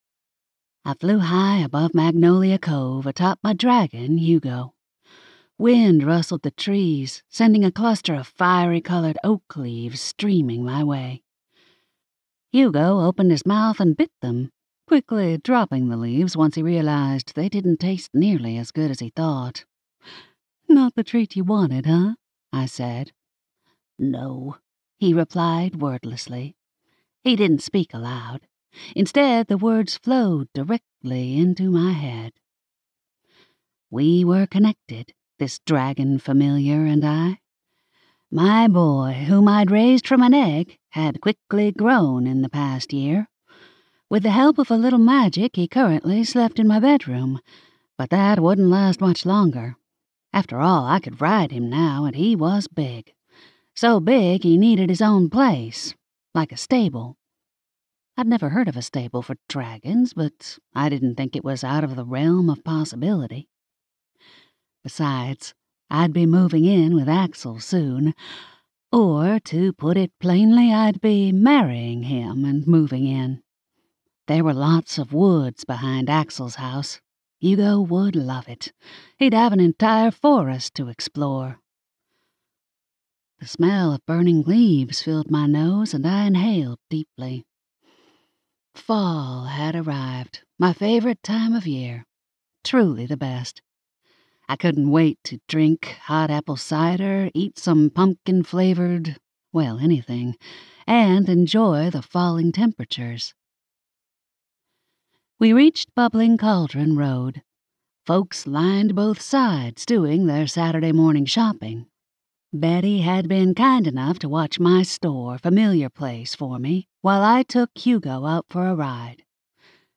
Southern-Wishes-audio_sample.mp3